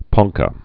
(pŏngkə)